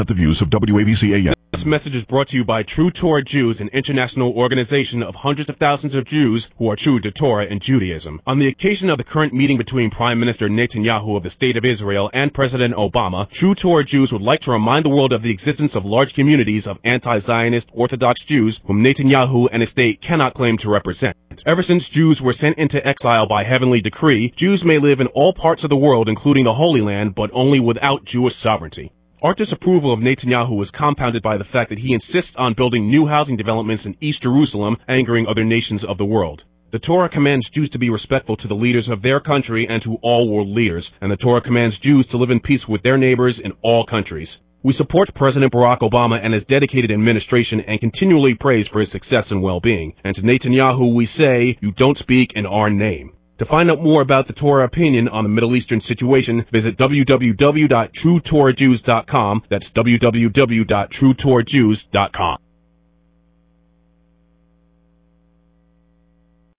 The ad ran once during the Rush Limbaugh program and then again during the Mark Levin show.
Radio Ads